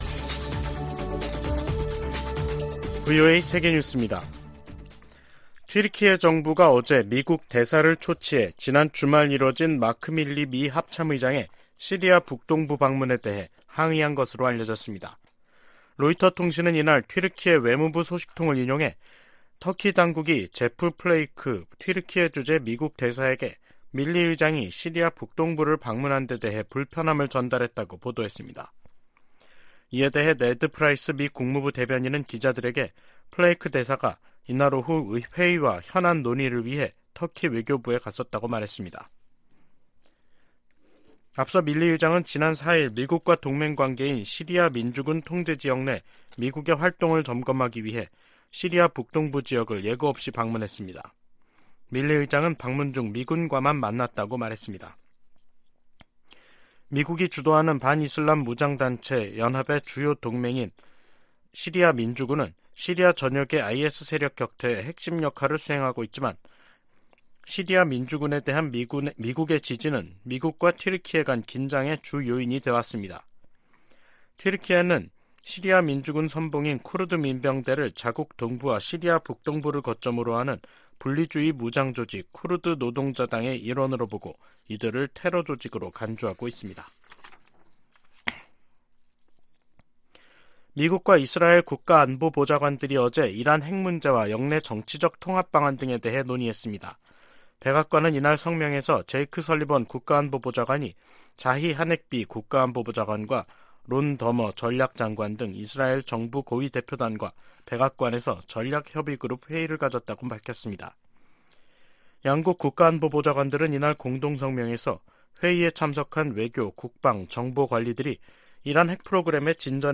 VOA 한국어 간판 뉴스 프로그램 '뉴스 투데이', 2023년 3월 7일 2부 방송입니다. 북한이 이달 또는 다음달 신형 고체 대륙간탄도미사일(ICBM)이나 정찰위성을 발사할 가능성이 있다고 한국 국가정보원이 전망했습니다. 미 국무부는 강제징용 문제 해법에 대한 한일 간 합의를 환영한다는 입장을 밝혔습니다. 한국이 역사 문제 해법을 발표한 데 대해 일본도 수출규제 해제 등으로 적극 화답해야 한다고 미국 전문가들이 주문했습니다.